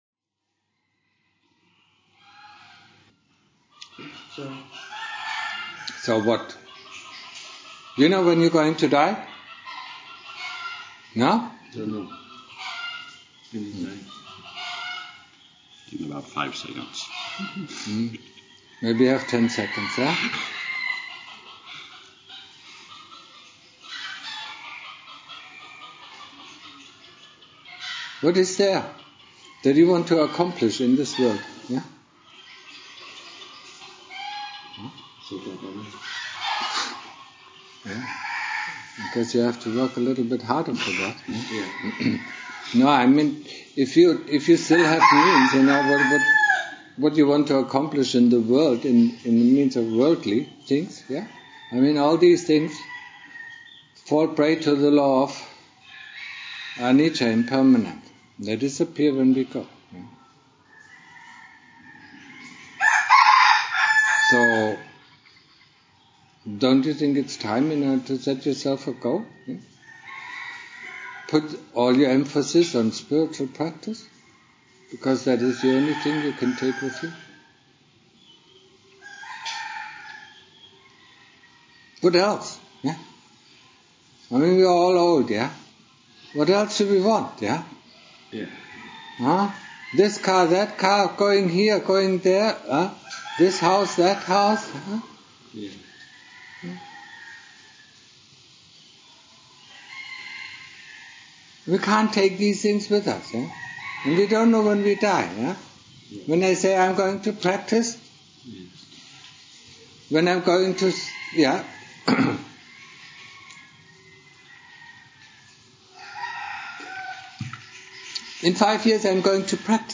Date Title Description Album Duration 28-12-16 Attachment to friends and family Talk to monks.